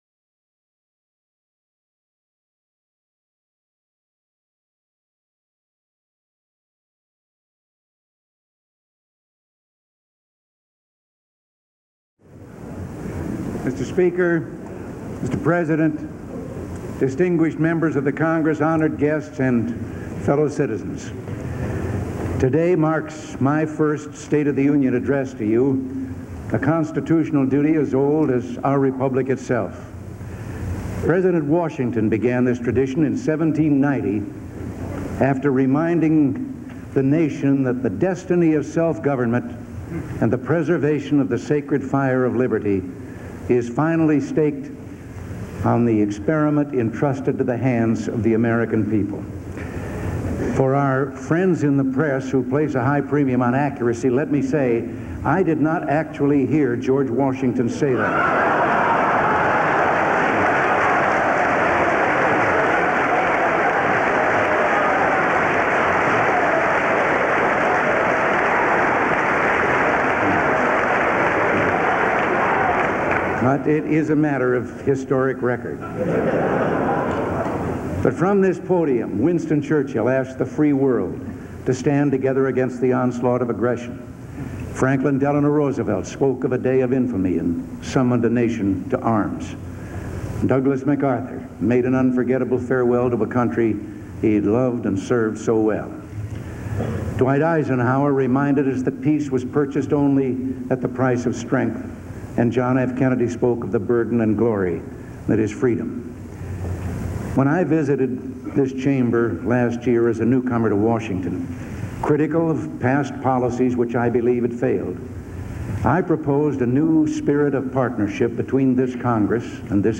January 26, 1982: State of the Union Address | Miller Center